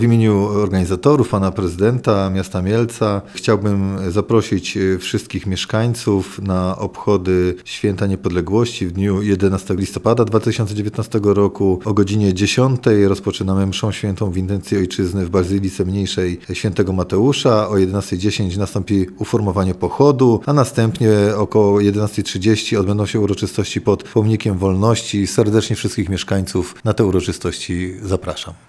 Poniedziałkowe obchody Święta Niepodległości w Mielcu jak co roku rozpoczną się liturgią mszy św. w intencji Ojczyzny w parafii św. Mateusza o godzinie 10.00. Mówi wiceprezydent Mielca, Paweł Pazdan.